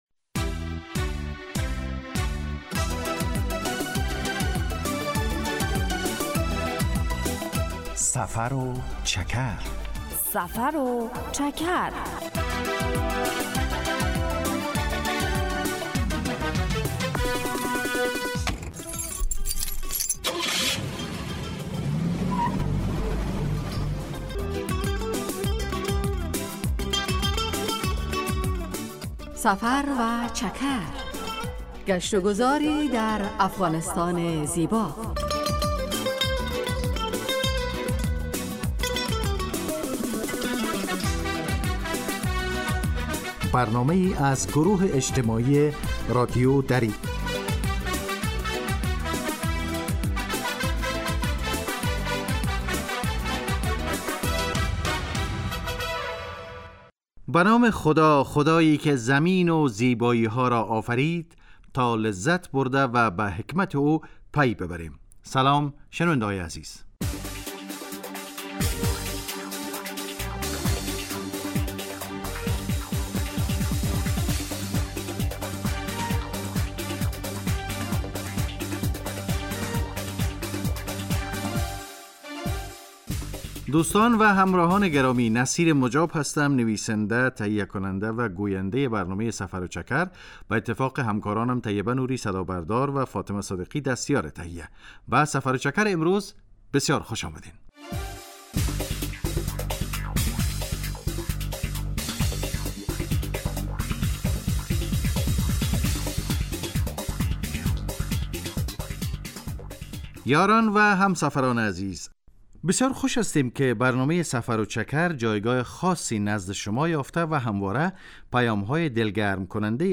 در سفر و چکر ؛ علاوه بر معلومات مفید، گزارش و گفتگو های جالب و آهنگ های متناسب هم تقدیم می شود.